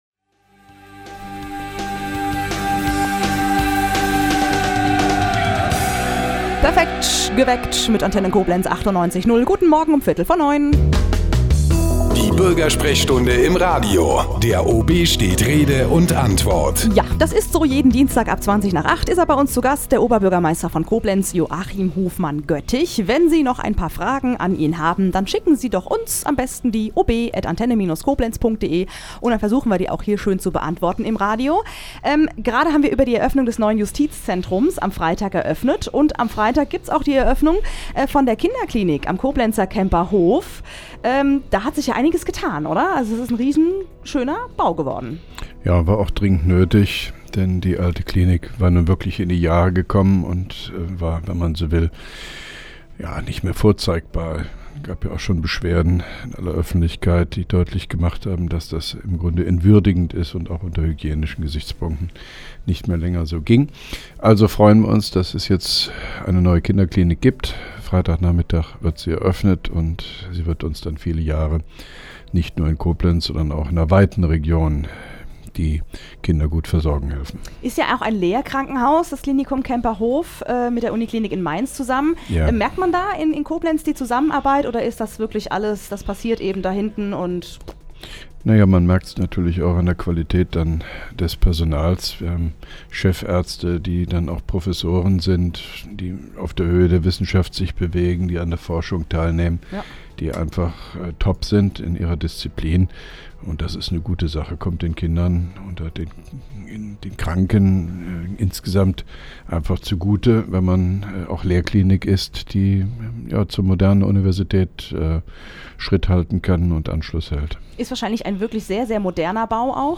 (3) Koblenzer Radio-Bürgersprechstunde mit OB Hofmann-Göttig 01.02.2011